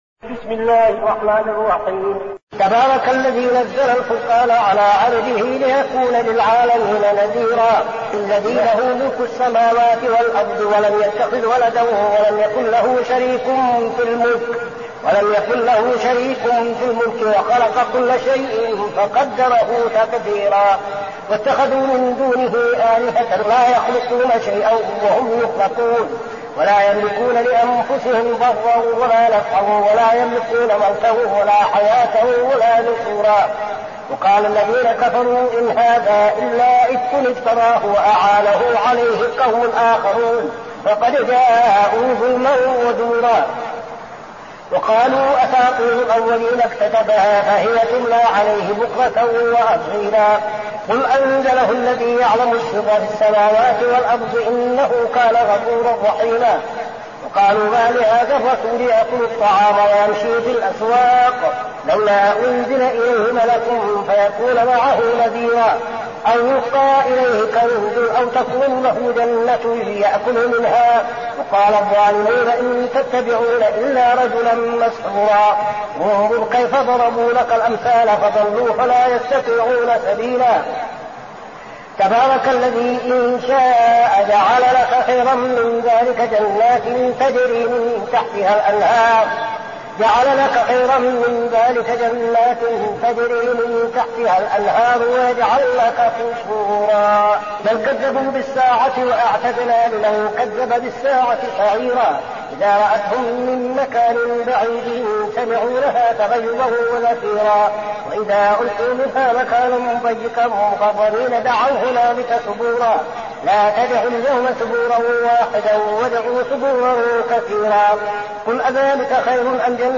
المكان: المسجد النبوي الشيخ: فضيلة الشيخ عبدالعزيز بن صالح فضيلة الشيخ عبدالعزيز بن صالح الفرقان